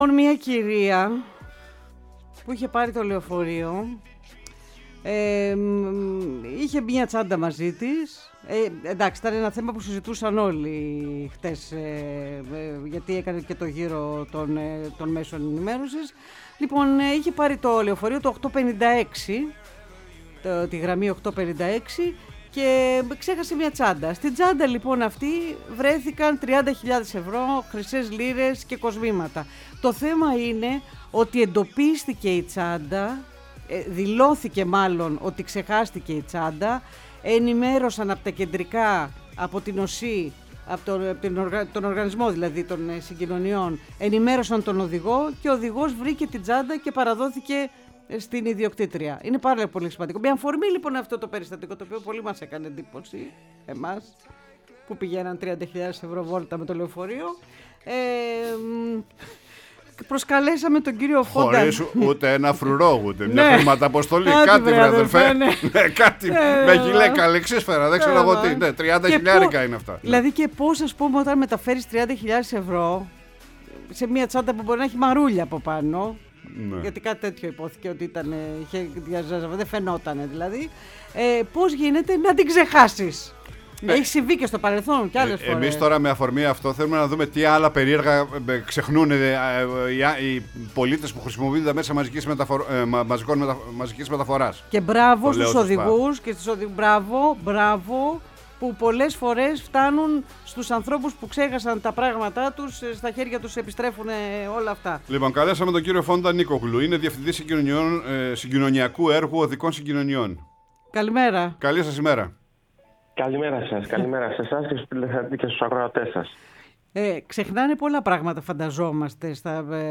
μίλησε στην εκπομπή «Πρωινή Παρέα»